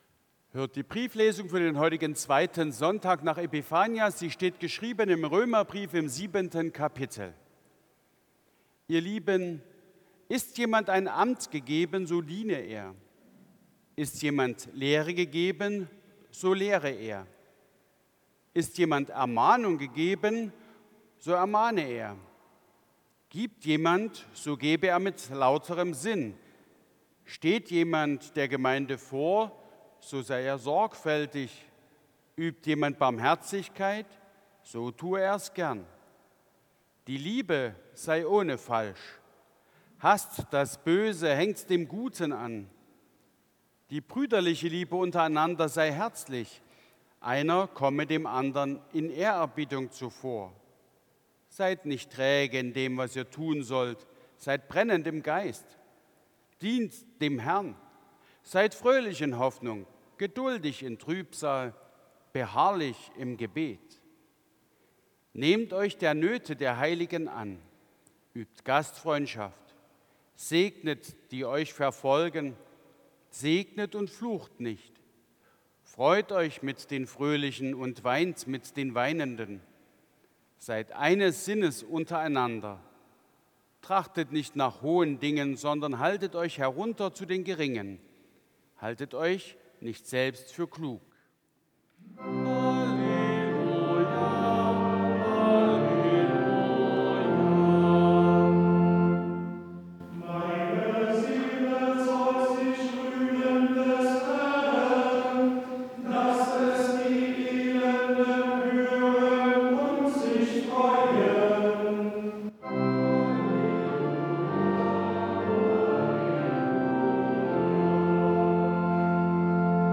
4. Brieflesung aus Römer 12,7-16 Ev.-Luth.
Audiomitschnitt unseres Gottesdienstes vom 2. Sonntag nach Epipanias 2026.